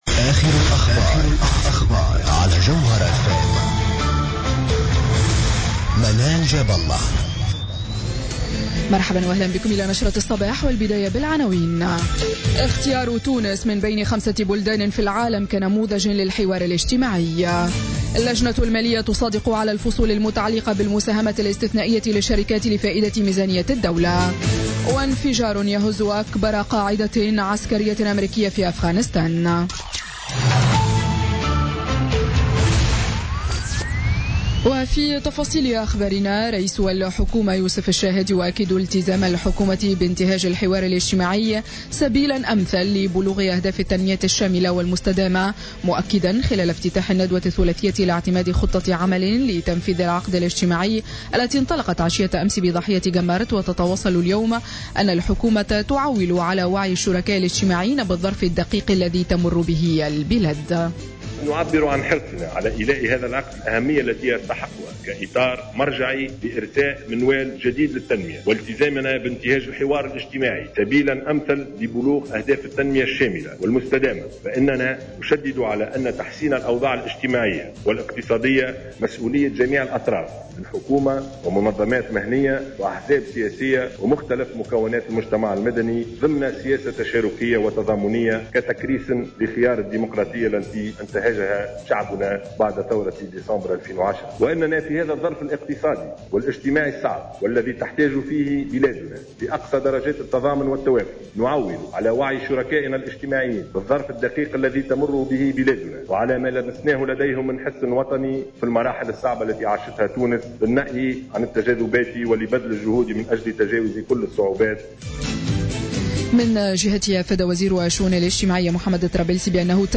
Journal Info 07h00 du samedi 12 Novembre 2016